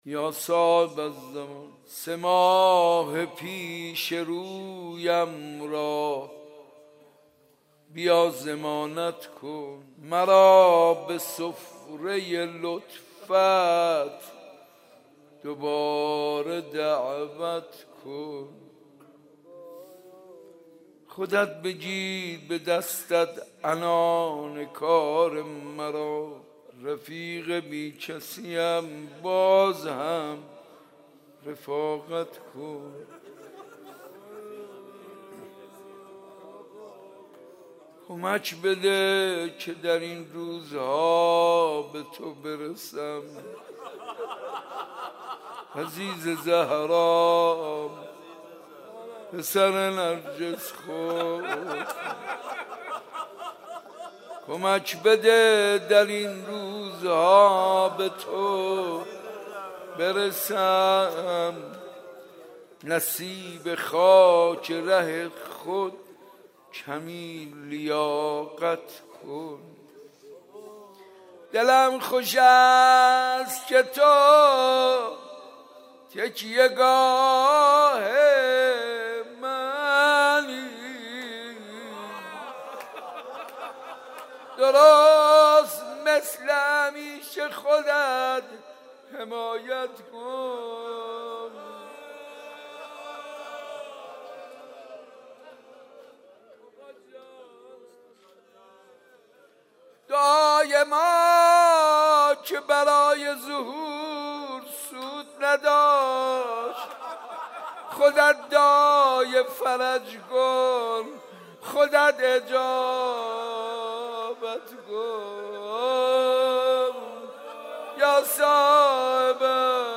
مناجات با امام زمان (عجل الله تعالی فرجه الشریف)
مدح امام باقر (علیه السلام)